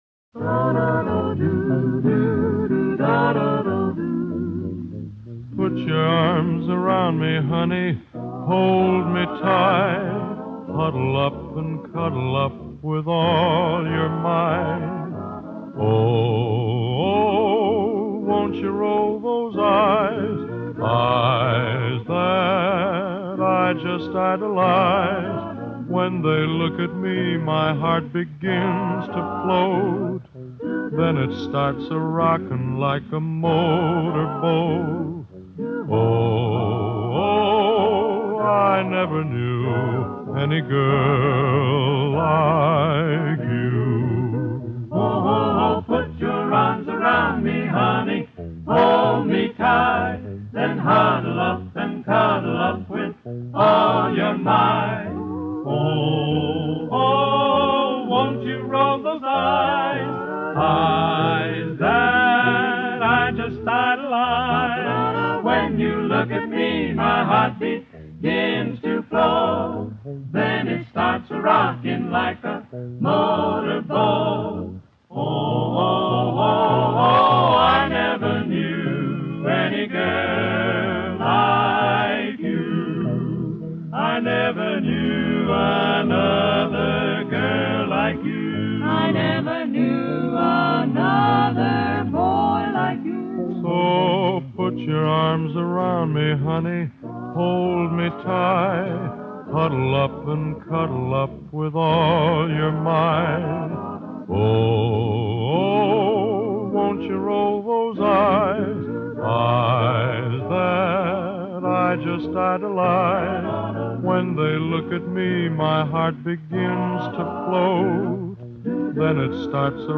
Обладатель баритона красивого глубокого тембра.